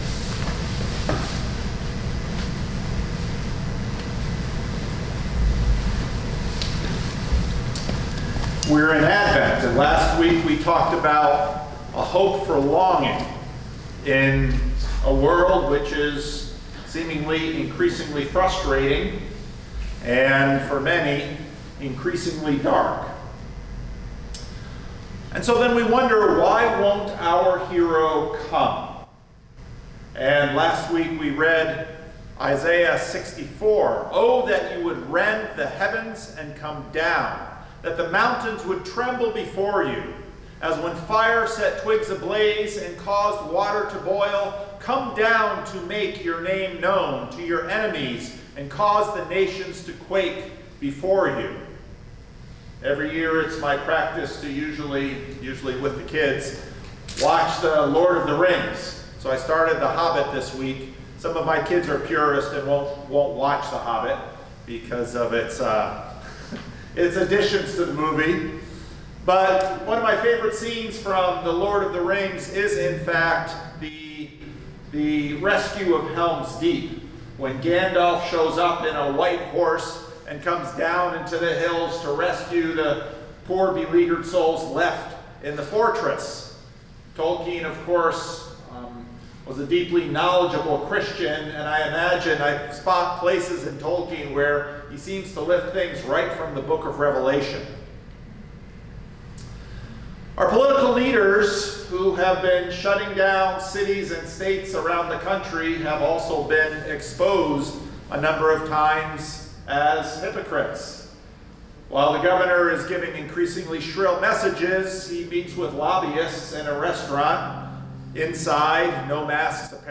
Sermons | Living Stones Christian Reformed Church